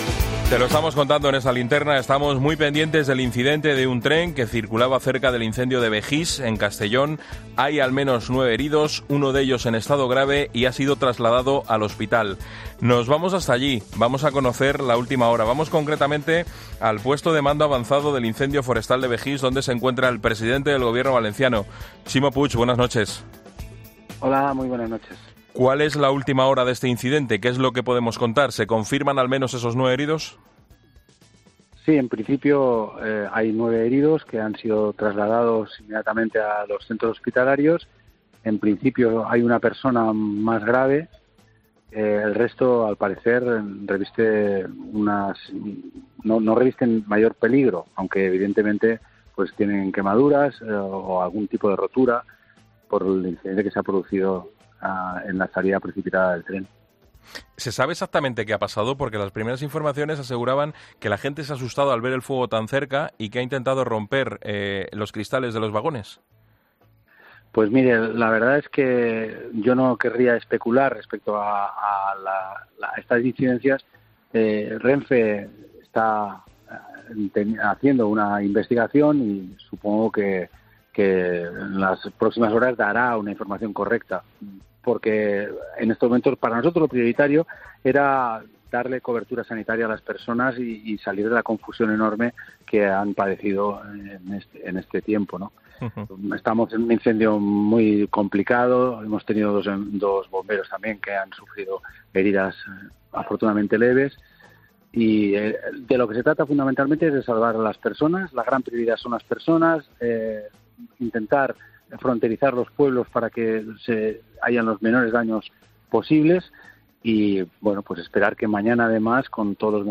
Ximo Puig cuenta en COPE lo último del incidente del tren que circulaba cerca del incendio de Bejís, Castellón